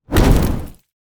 fire_punch_02.wav